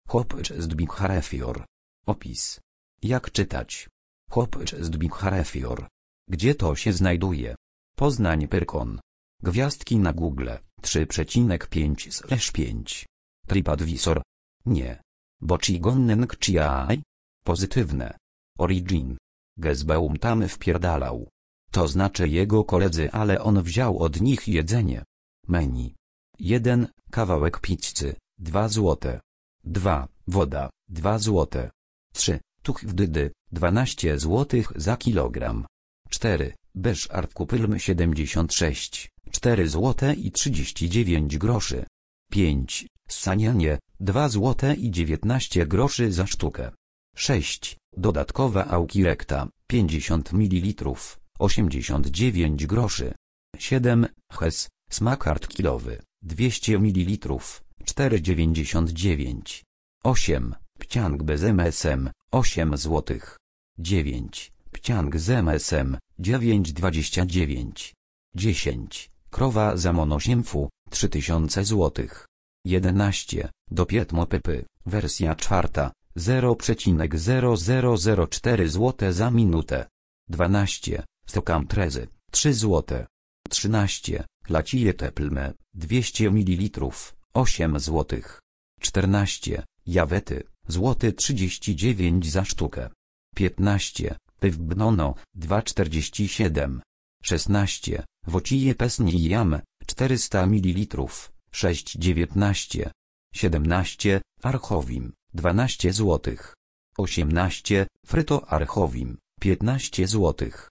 Hobj_stbiqharefior_-_audiodeskrypcja.mp3